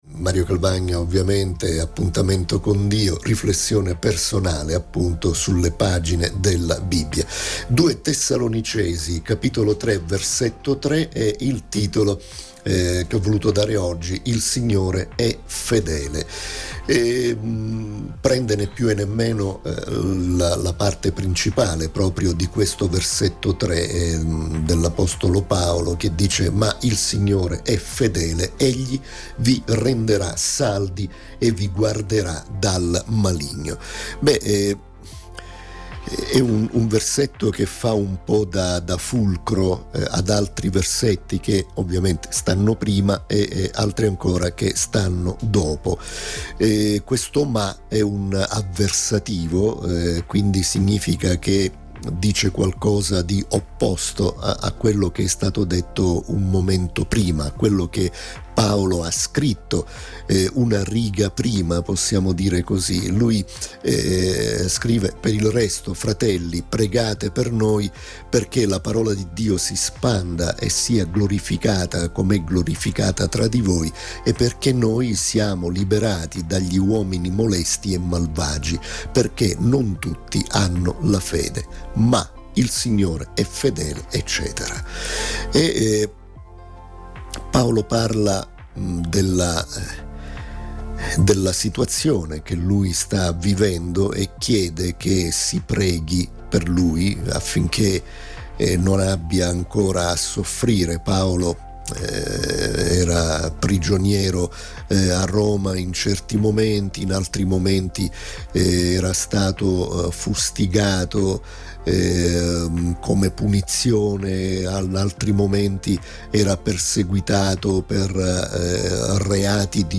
Riflessione